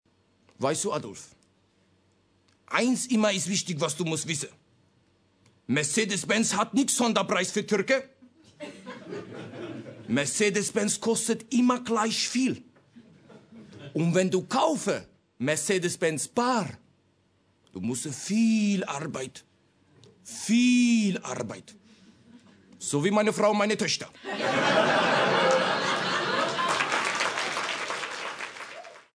(Comedy)